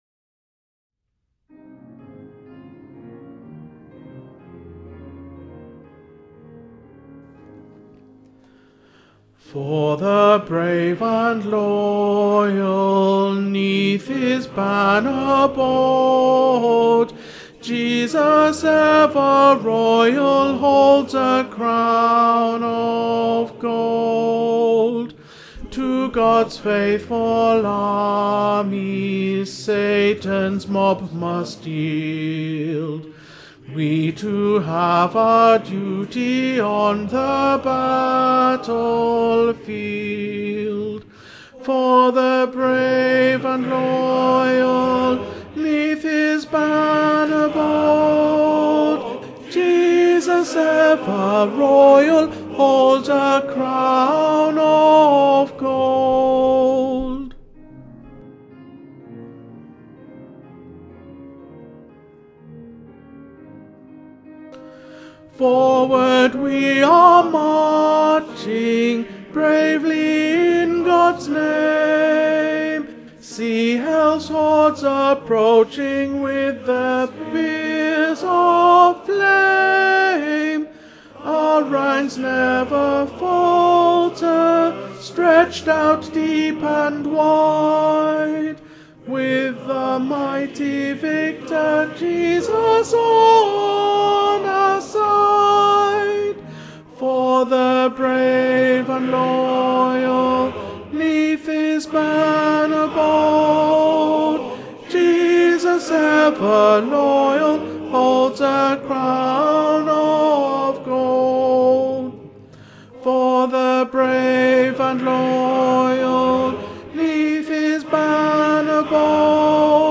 Rachie-tenor-1.mp3